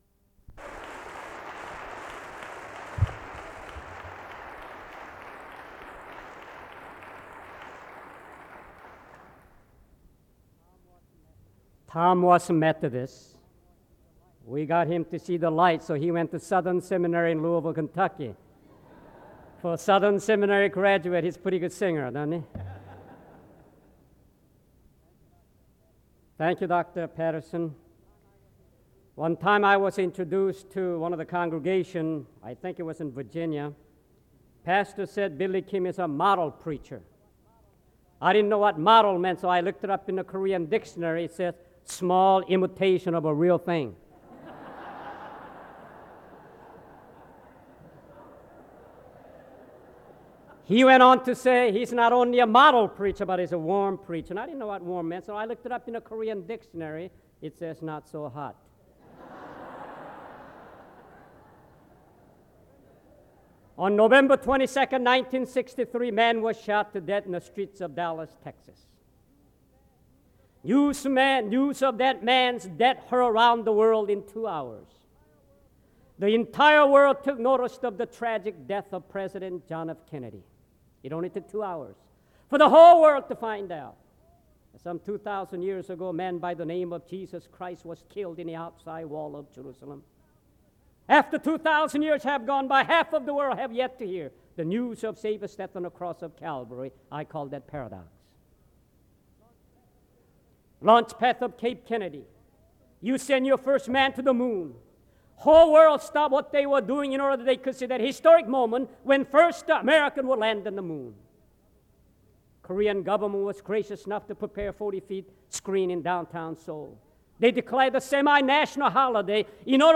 SEBTS Chapel - Billy Kim October 16, 1996
In Collection: SEBTS Chapel and Special Event Recordings SEBTS Chapel and Special Event Recordings - 1990s Thumbnail Title Date Uploaded Visibility Actions SEBTS_Chapel_Billy_Kim_1996-10-16.wav 2026-02-12 Download